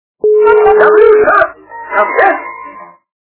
При прослушивании Из м.ф. Простоквашино - Гаврюша! Ко мне! качество понижено и присутствуют гудки.